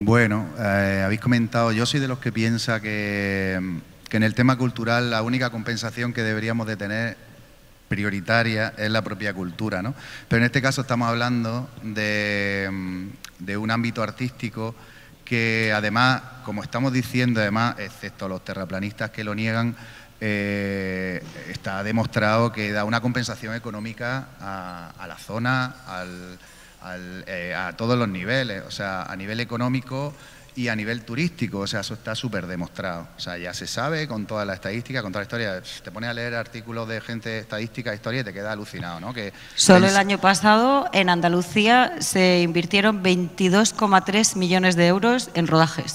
El Patio de Luces de Diputación recibe el habitual espacio de debate que tiene en cada edición de FICAL la Asociación de Técnicos y Empresas del Sector Audiovisual de Almería (TESA)